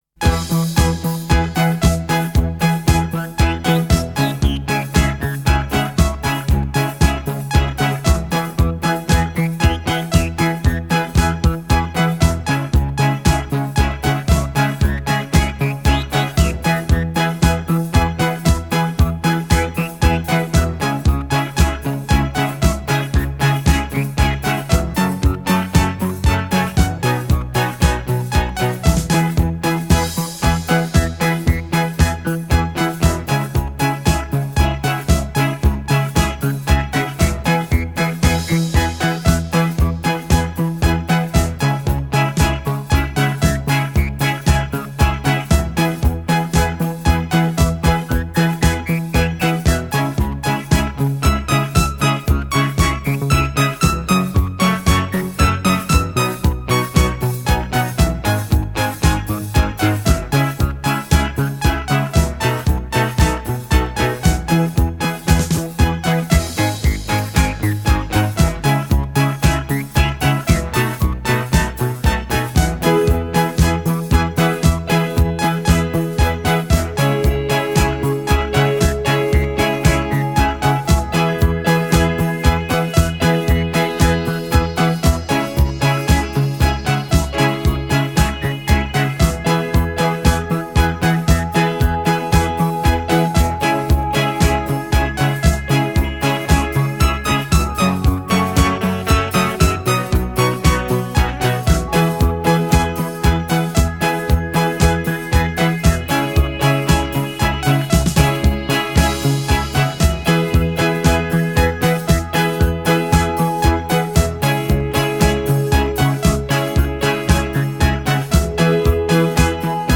Filed under coldwave, electronic